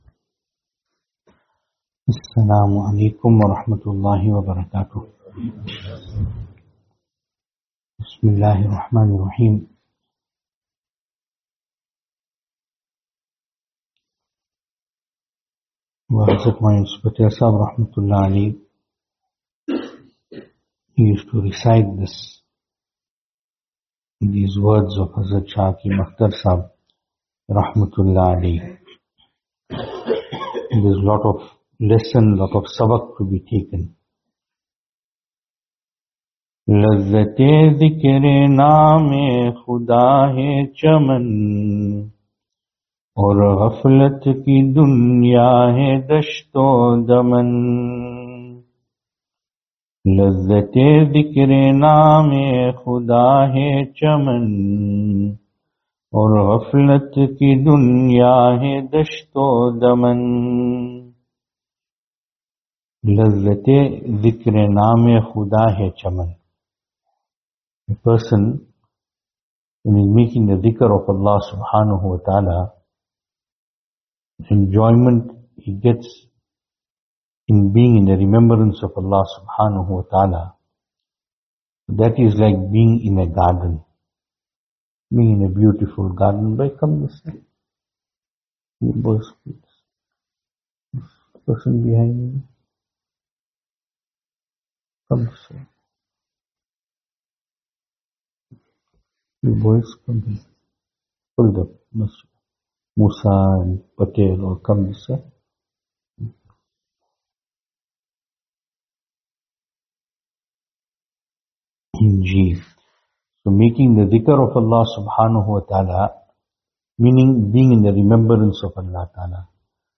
Venue: Albert Falls , Madressa Isha'atul Haq
Service Type: Islahi Majlis